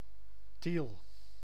Tiel (Dutch pronunciation: [til]